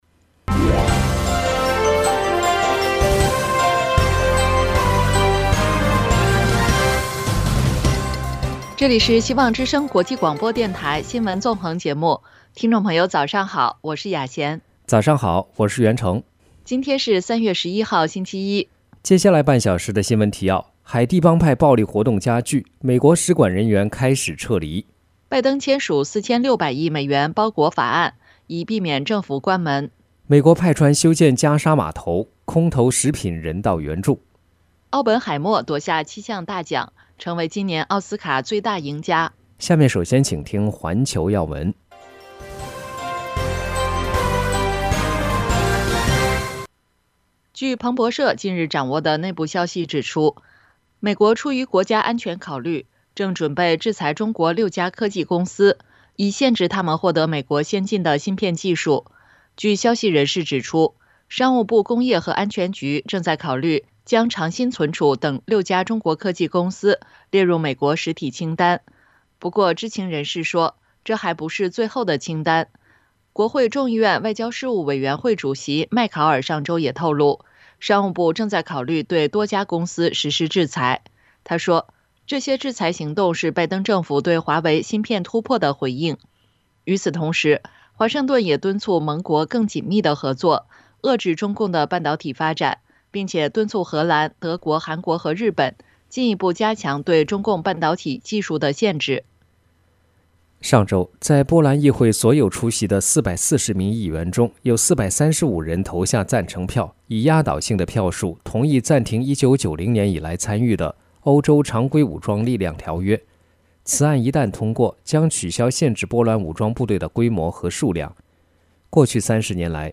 拜登签署4600亿美元包裹法案 以避免政府关门【晨间新闻】